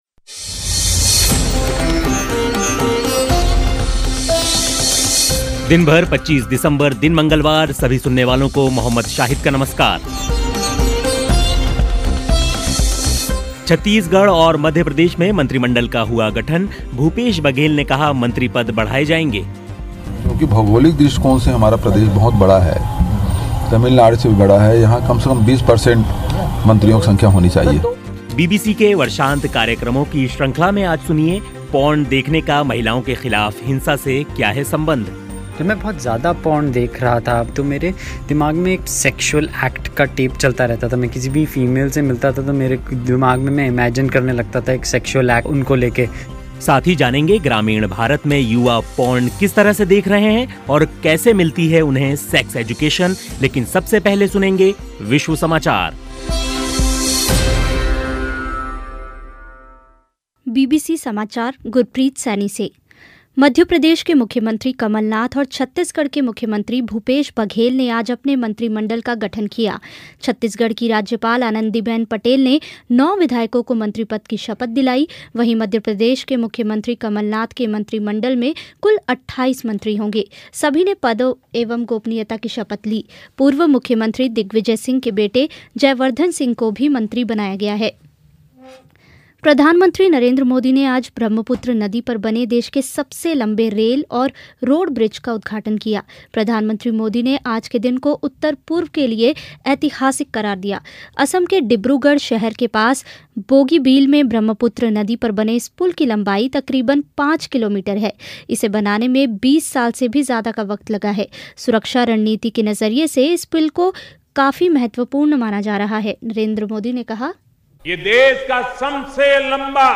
सुनेंगे विश्व समाचार.